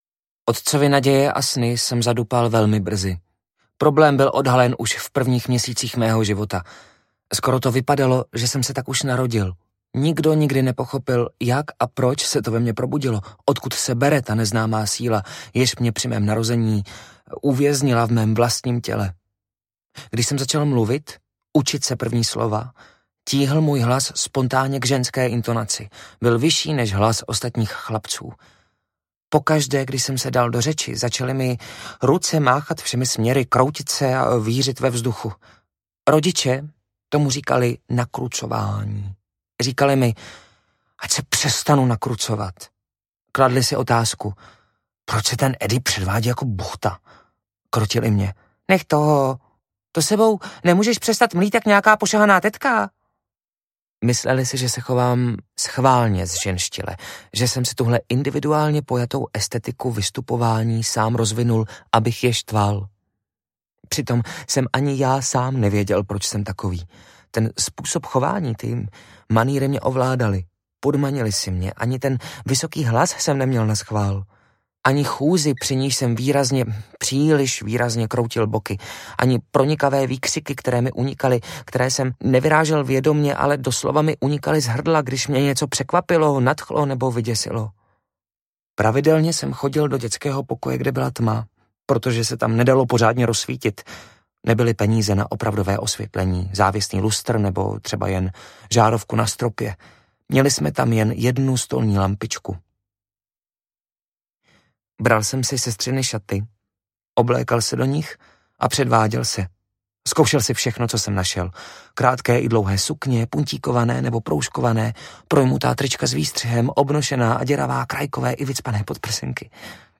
Skoncovat s Eddym B. audiokniha
Ukázka z knihy
Vyrobilo studio Soundguru.